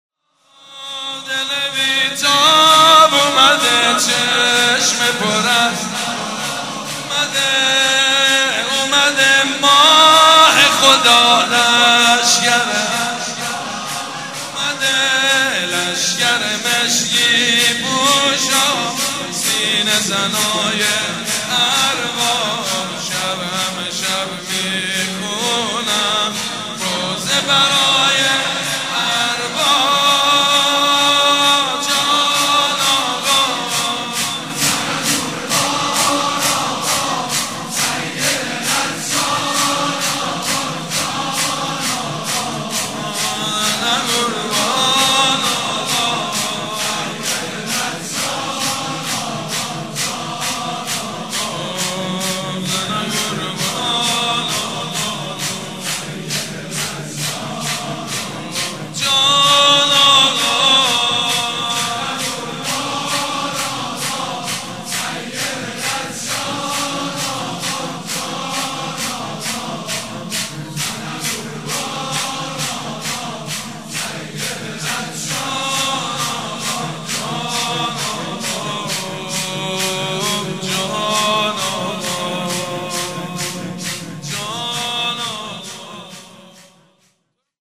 مناسبت : شب هجدهم رمضان
مداح : سیدمجید بنی‌فاطمه قالب : شور